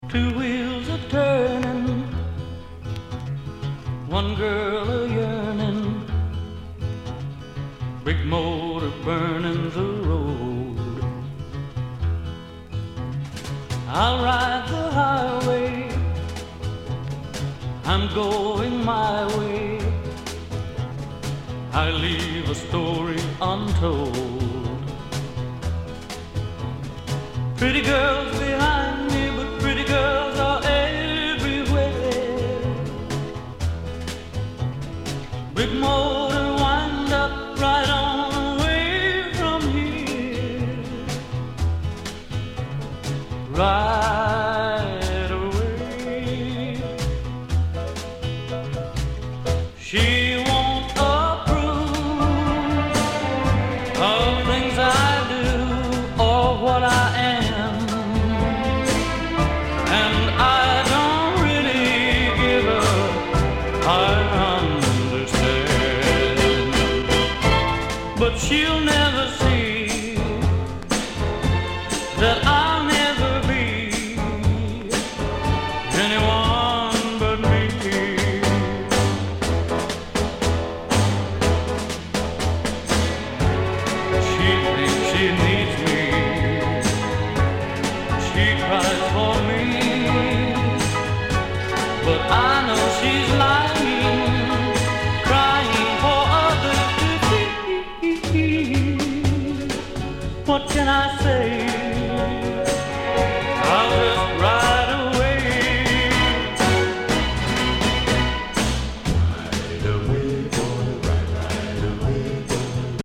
Genre: Rock 'n' Roll